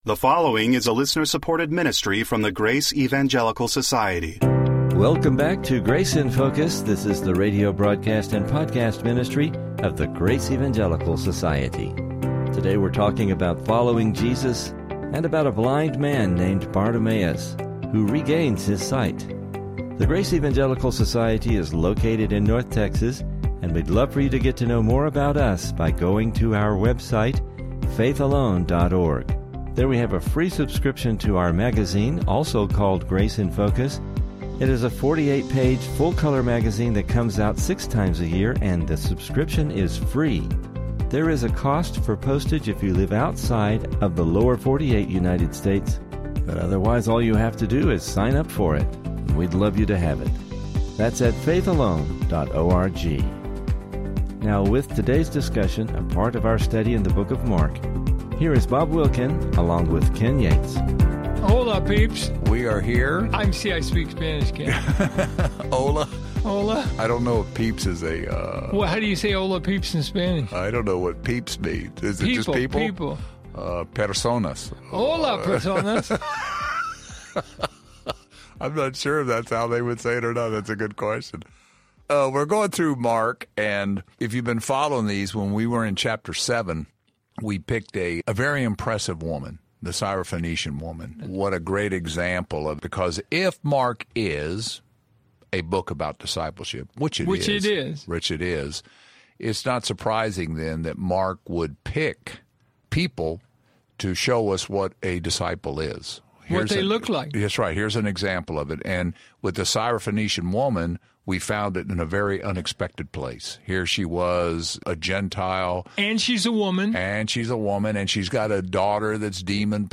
In this chapter, a blind man named Bartimaeus regains his sight. After he is healed, he follows Jesus on the road to Jerusalem. Please listen for an interesting discussion and lessons related to this miracle!